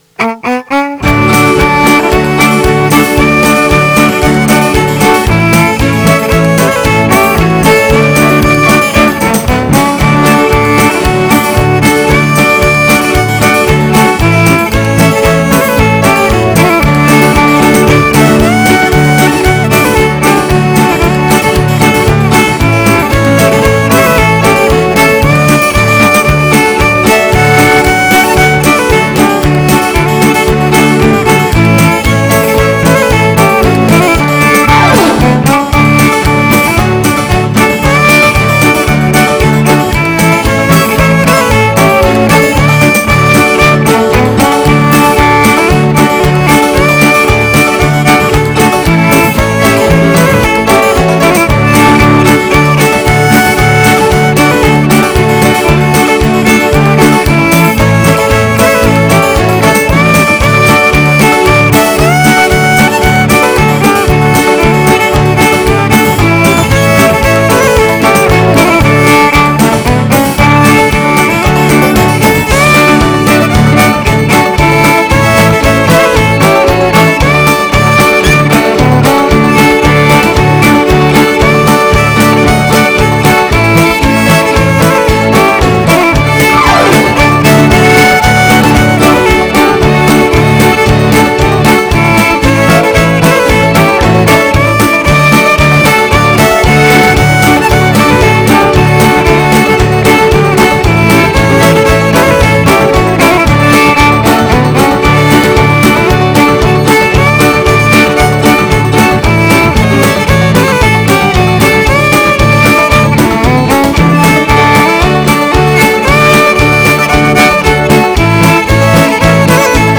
guitare
clavier
fiddle
guitar
keyboard